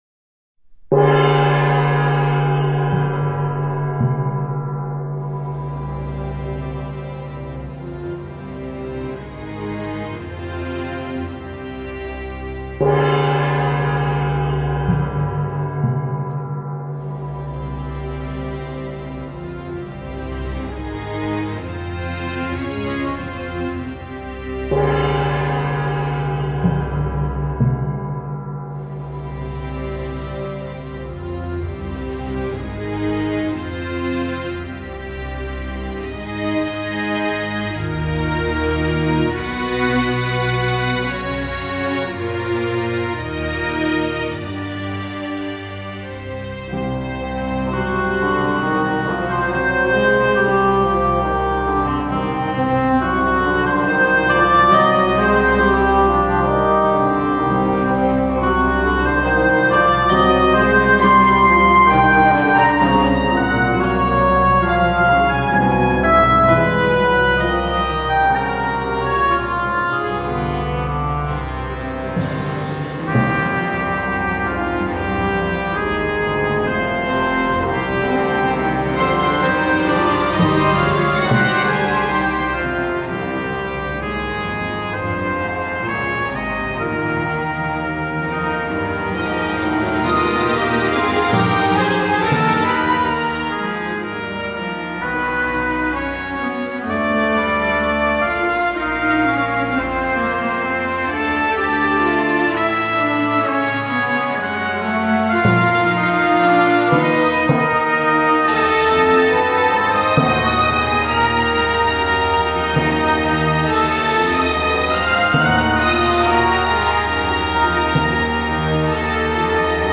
Computer generated